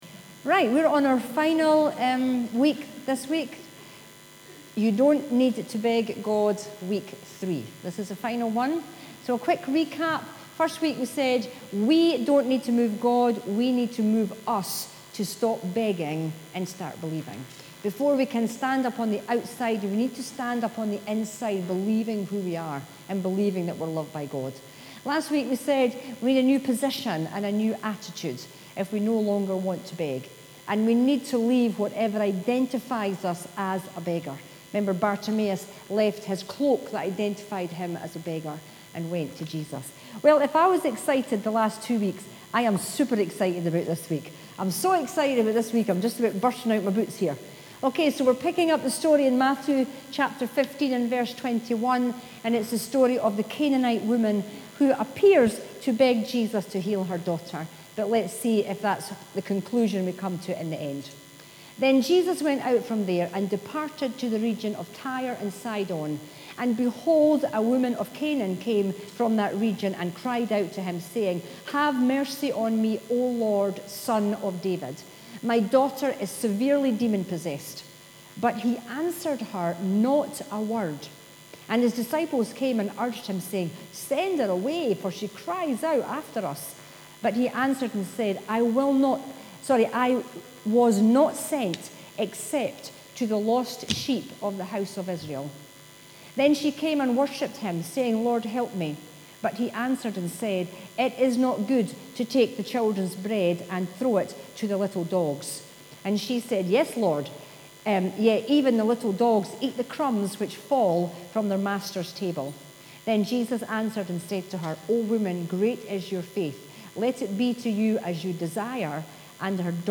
Sermons 2022 – Dunfermline Elim Pentecostal Church